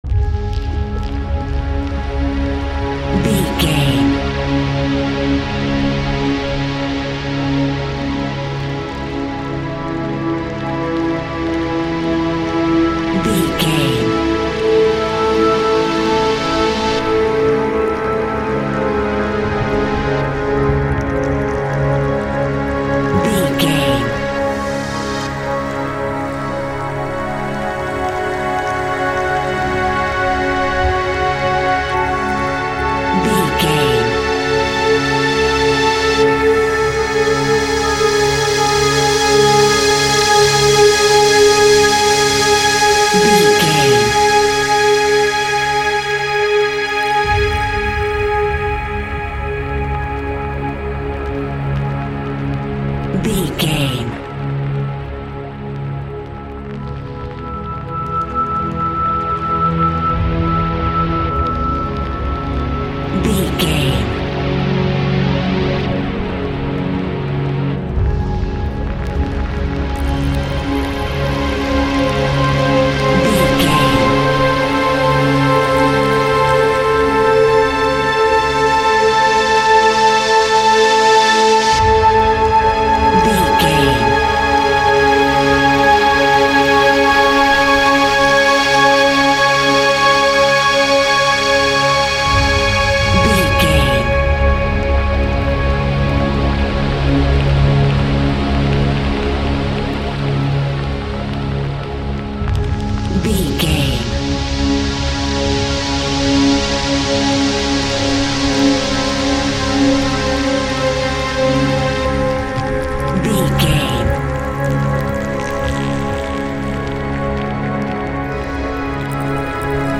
In-crescendo
Thriller
Aeolian/Minor
tension
ominous
suspense
haunting
eerie
horror
synthesizers
Synth Pads
atmospheres